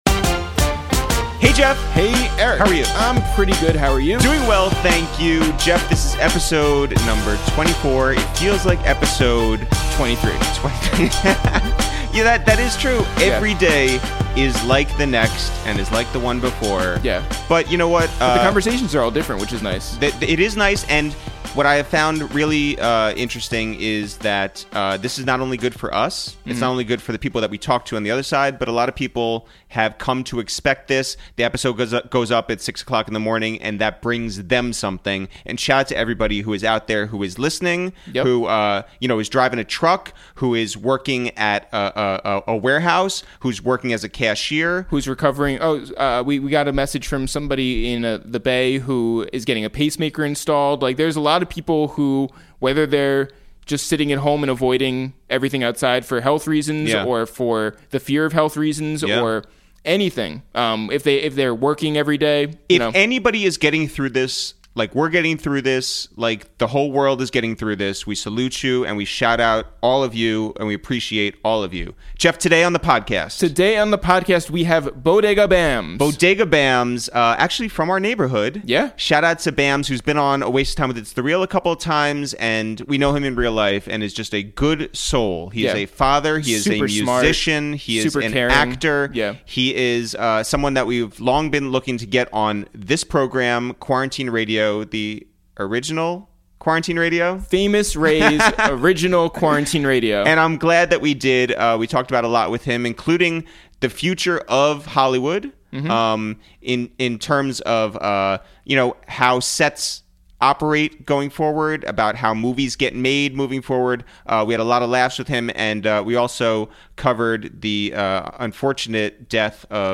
Today on Episode 24 of Quarantine Radio, we make calls from our Upper West Side apartment to check in on rapper/actor Bodega Bamz to talk about waking up with purpose and gratitude, what the future of film sets will look like moving forward, and the tragic passing of Chynna.